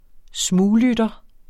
Udtale [ ˈsmuːˌlydʌ ]